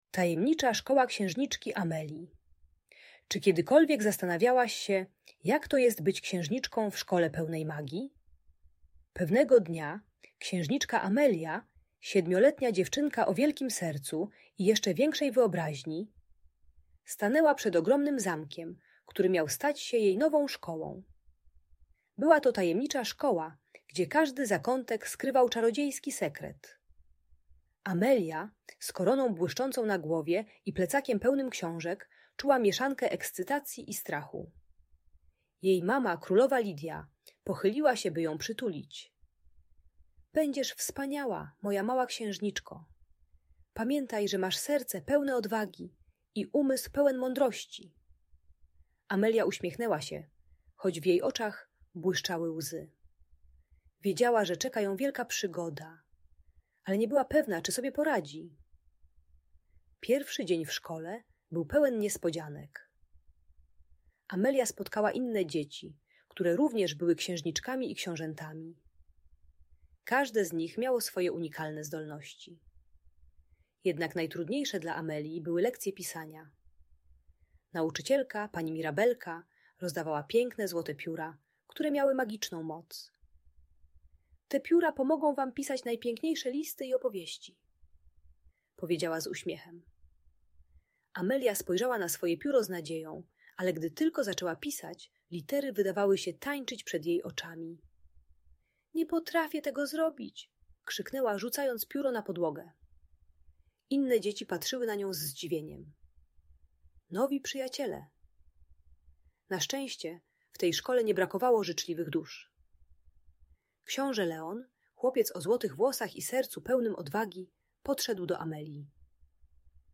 Tajemnicza Szkoła Księżniczki Amelii - Audiobajka dla dzieci